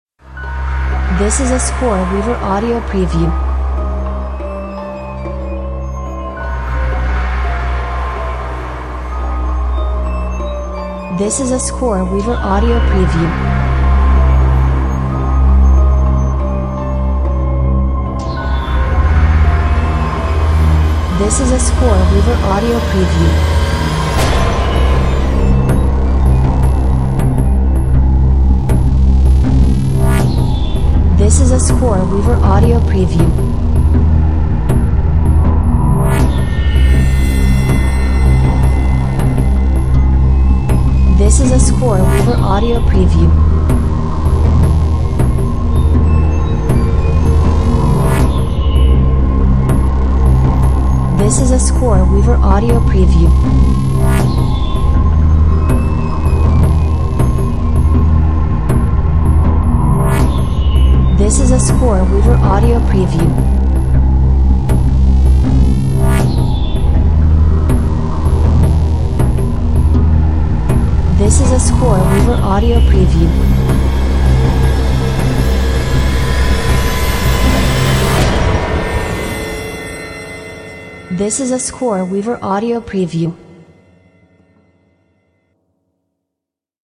Eerie horror movie underscore!